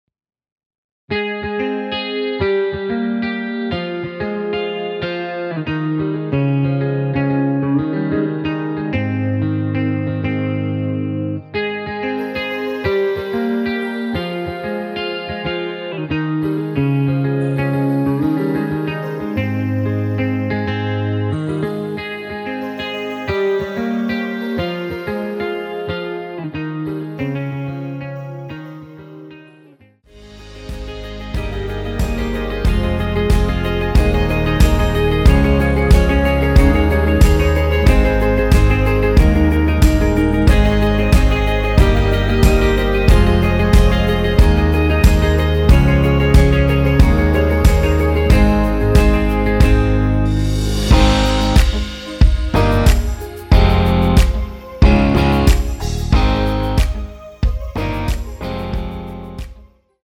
원키에서(-1)내린 (2절 삭제)하고 진행 되는 멜로디 포함된 MR입니다.(미리듣기 확인)
Ab
멜로디 MR이란
앞부분30초, 뒷부분30초씩 편집해서 올려 드리고 있습니다.
중간에 음이 끈어지고 다시 나오는 이유는